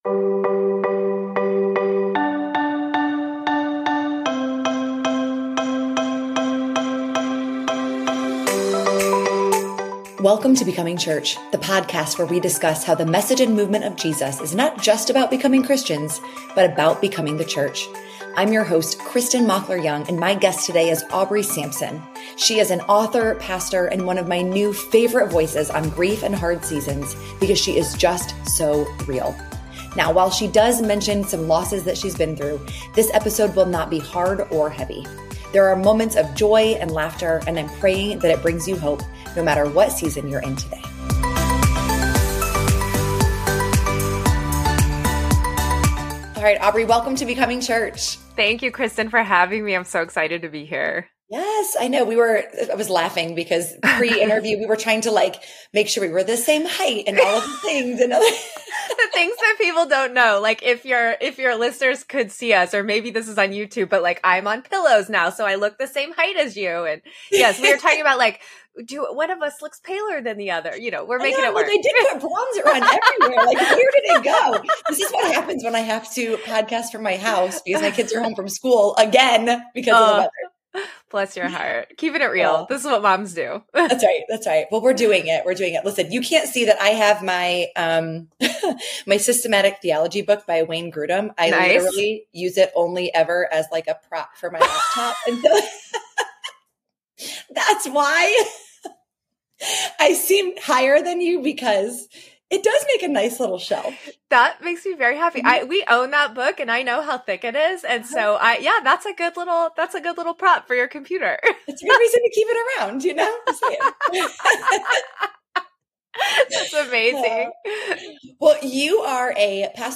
There is freedom in naming your feelings and being honest with God about where you place your blame. This conversation will give you permission to